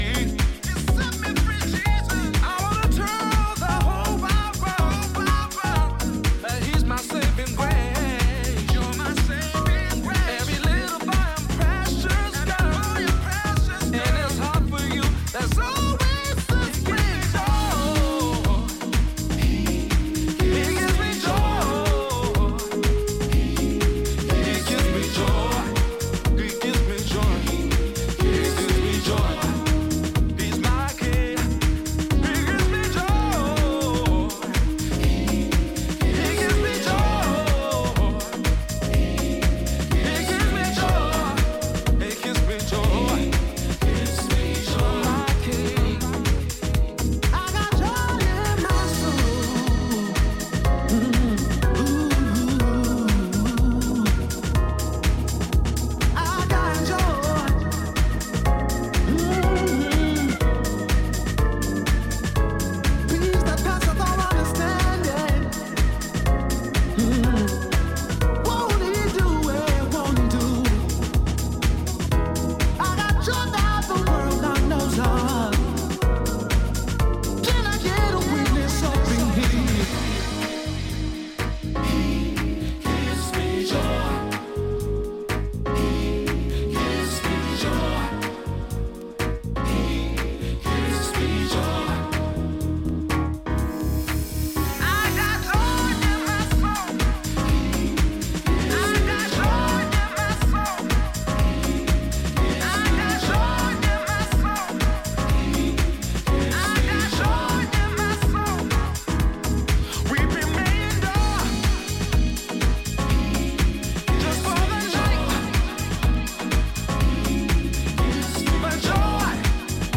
Italian deep house
lays down warm keys, rolling percussion and fluid basslines